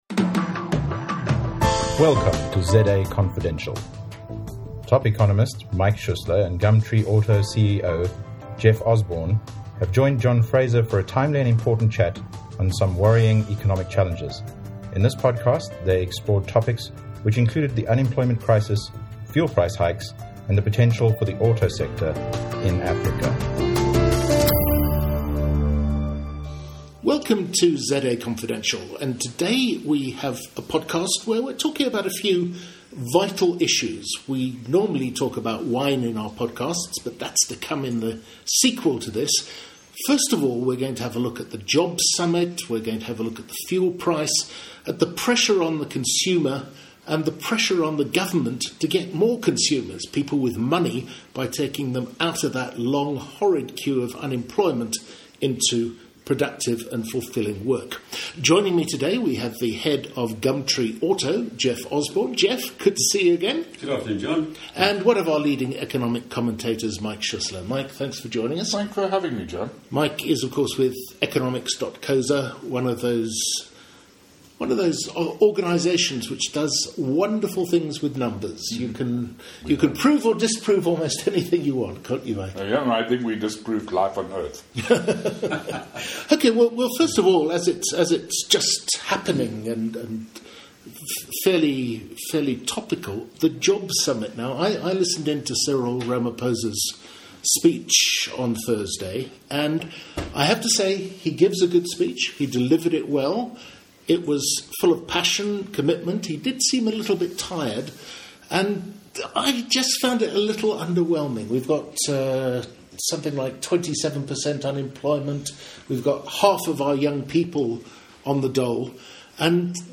Jobs Summit Discussion